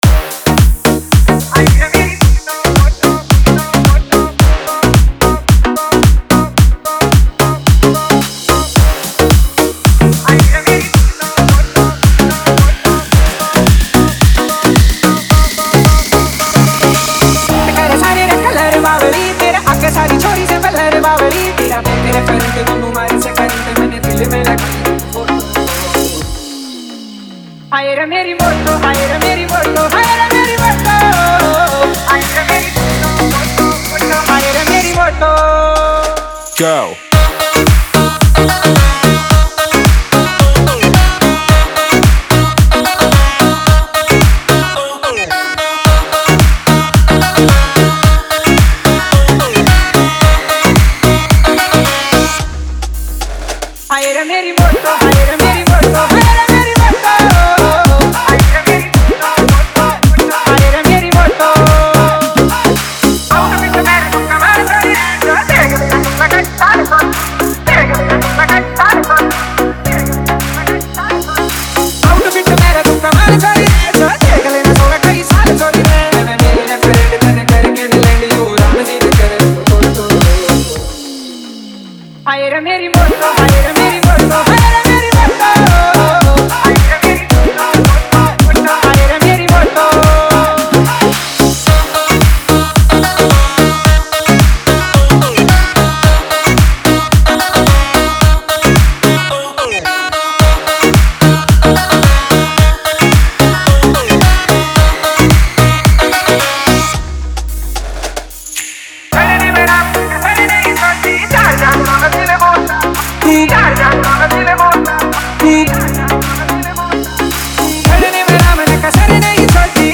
Haryanvi DJ Remix Songs